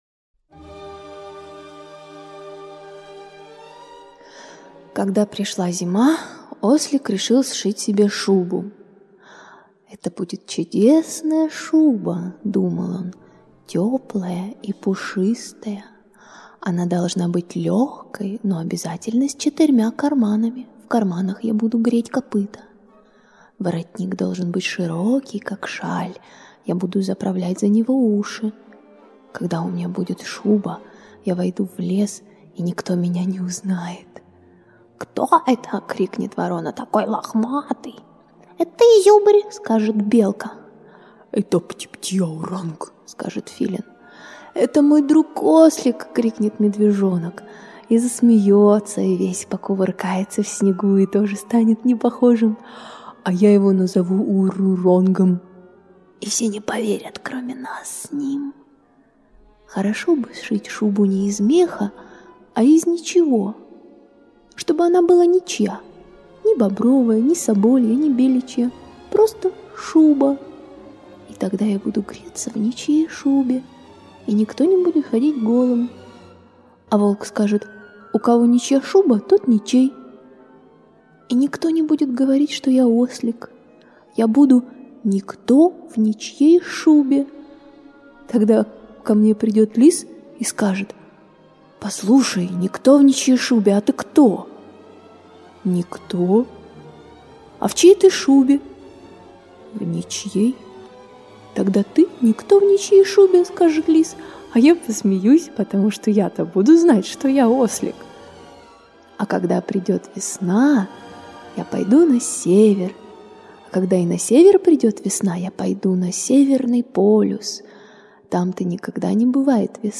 Как Ослик шил шубу – Козлов С.Г. (аудиоверсия)